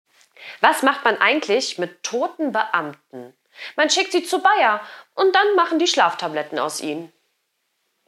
Die Witzschmiede bringt Dir jeden Tag einen frischen Witz als Audio-Podcast. Vorgetragen von unseren attraktiven SchauspielerInnen.
Comedy , Unterhaltung , Kunst & Unterhaltung